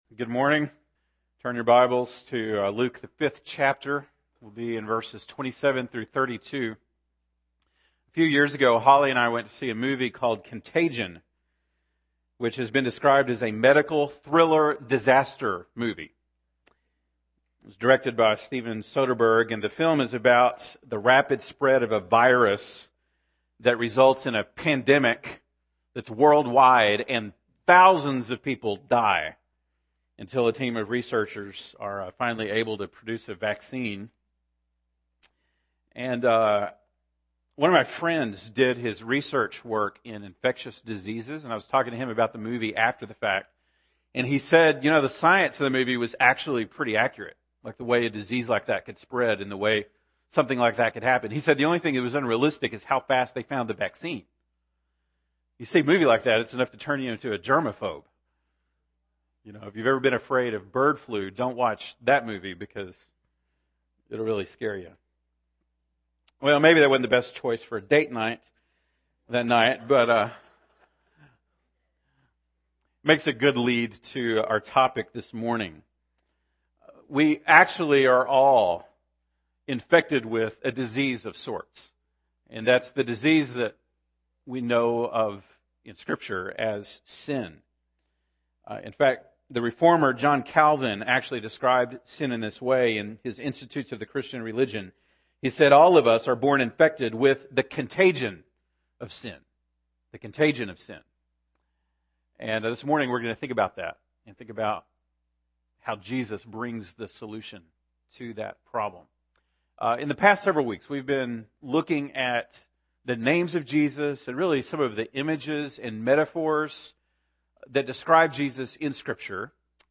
January 10, 2016 (Sunday Morning)